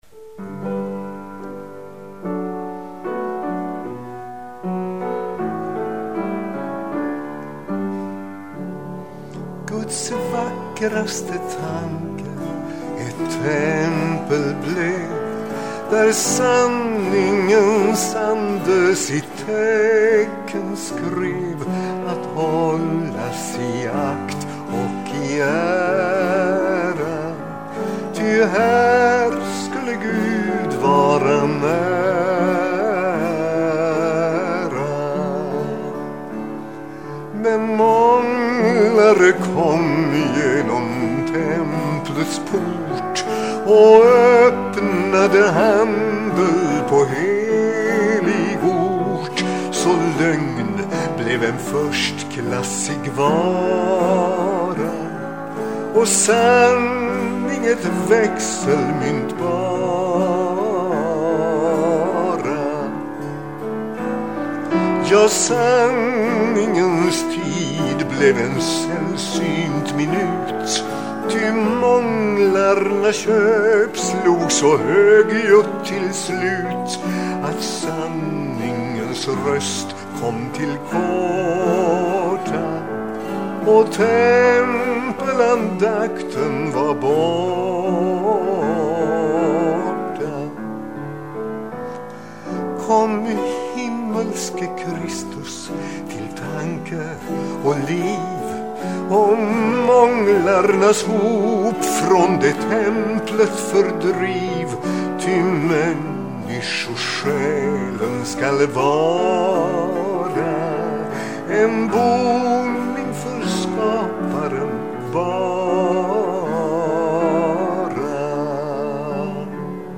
Det rör sig om inspelningar med sång till eget pianoackompanjemang.
Jag ber den som lyssnar notera att alla visorna är tagna ”live”.